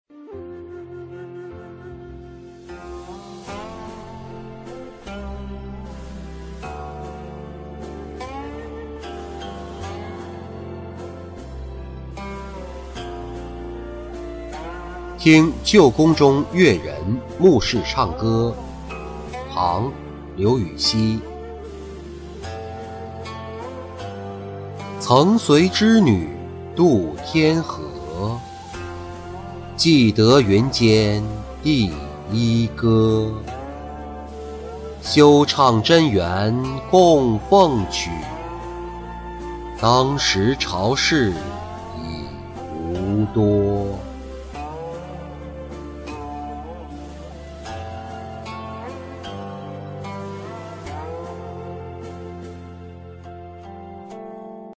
听旧宫中乐人穆氏唱歌-音频朗读